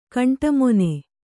♪ kaṇṭamone